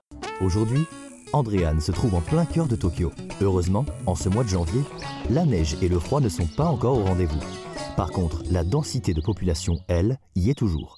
French Vocalist Male